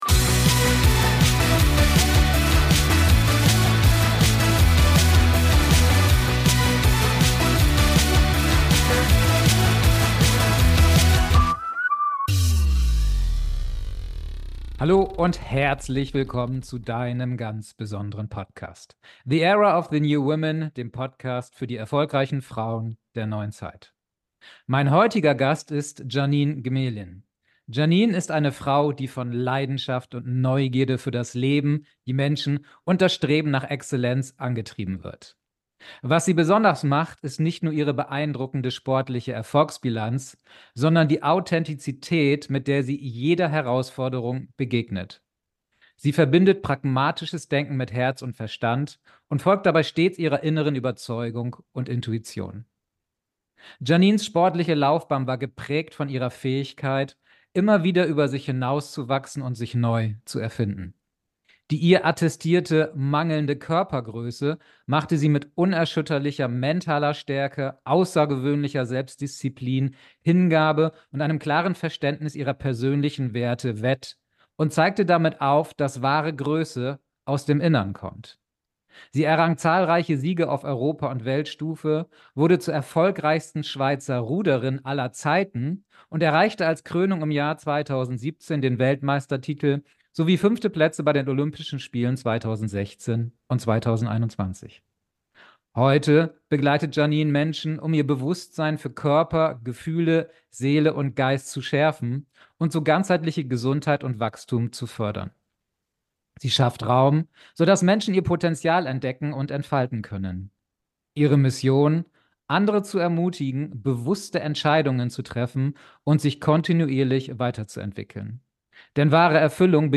#038 Commitment, Herzblut und eiserner Wille. Das Interview mit Ruder-Weltmeisterin Jeannine Gmelin ~ The Era of the New Women Podcast